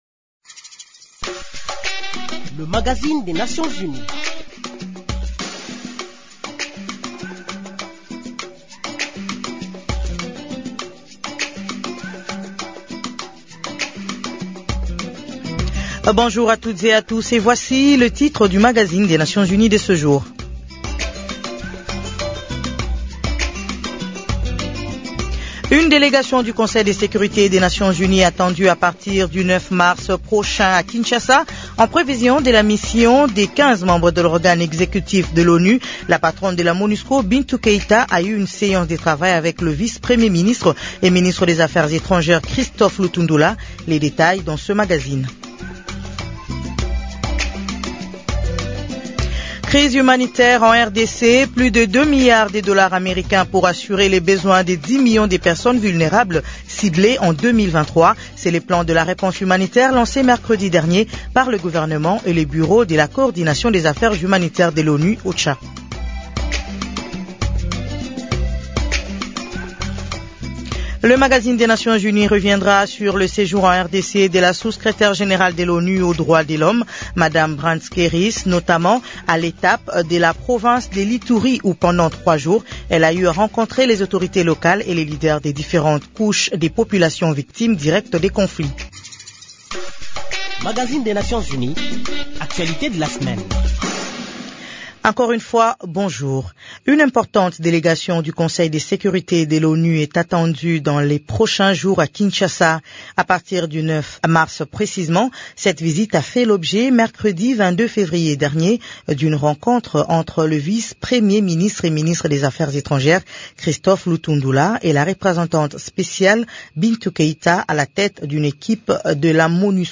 Peu avant de quitter Kinshasa, Mme Martha Pobee et Mme Elizabeth Spehar, Sous-Secrétaire générale en charge de l’Appui à la paix, qui était également de la mission, ont répondu aux questions de Radio Okapi et de l’AFP.